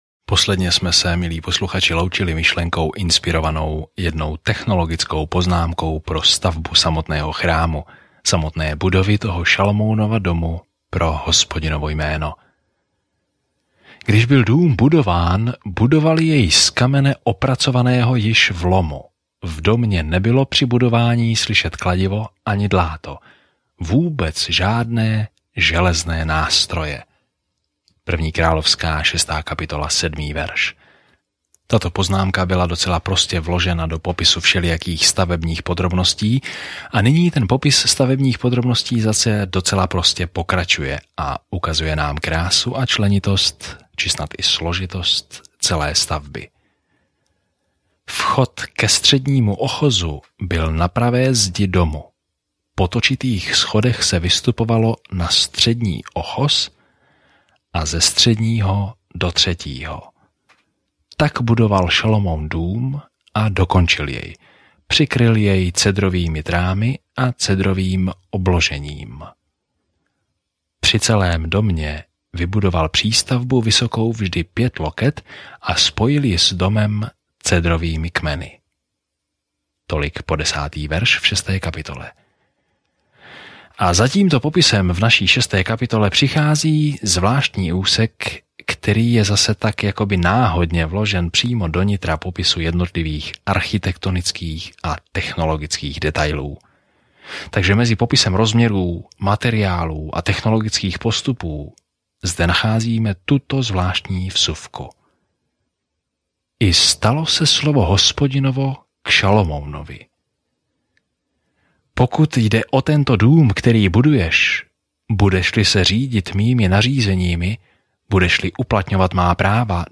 Písmo 1 Královská 6:8-38 1 Královská 7:1-14 Den 7 Začít tento plán Den 9 O tomto plánu Kniha králů pokračuje v příběhu o tom, jak izraelské království za Davida a Šalomouna vzkvétalo, ale nakonec se rozpadlo. Denně cestujte po 1. králi, poslouchejte audiostudii a čtěte vybrané verše z Božího slova.